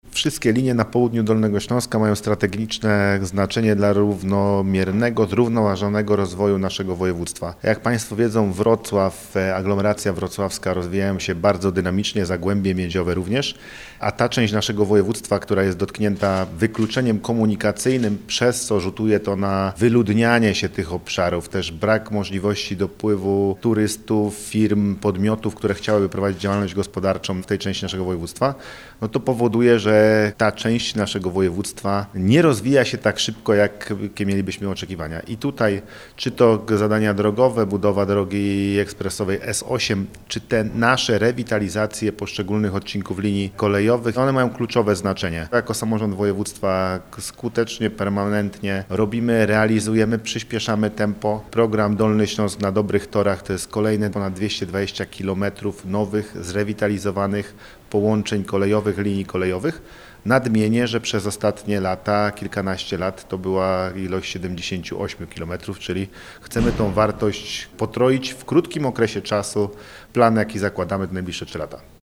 Program „Dolny Śląsk na dobrych torach” to jest kolejnych ponad 220 km nowych, zrewitalizowanych połączeń linii kolejowych – mówi Paweł Gancarz, Marszałek Województwa Dolnośląskiego.